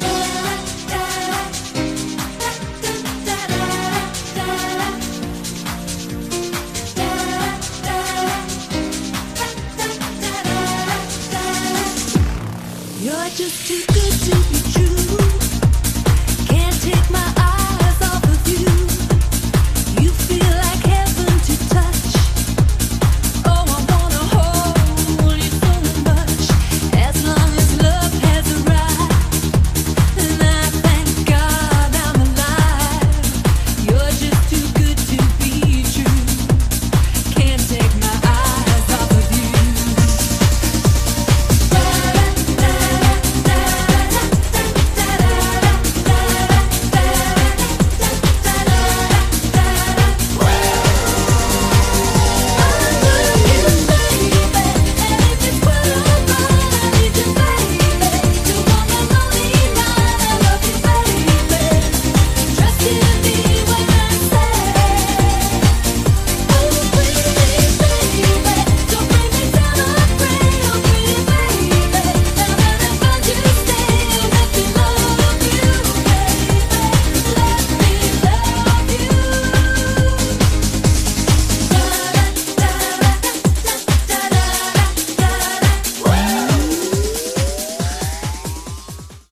BPM137--1
Audio QualityMusic Cut
- Music from custom cut